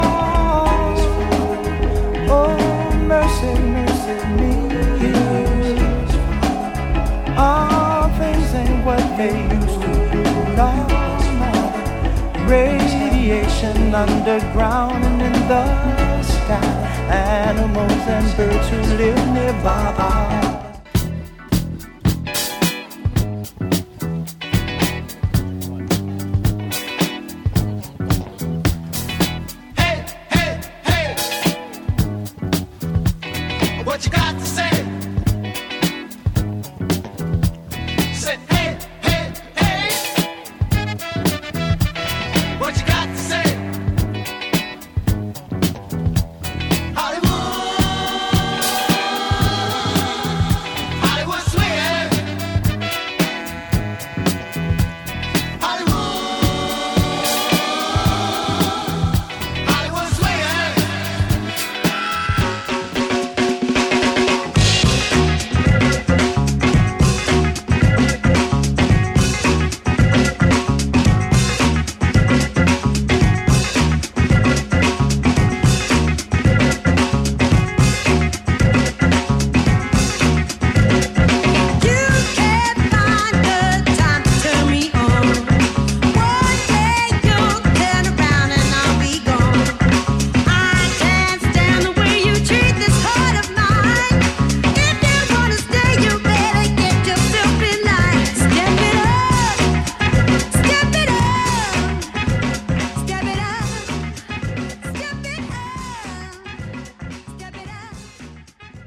Funk and Soul Hits All Day
Mix of Funk and Soul Hits for All Day